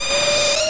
chargejump.wav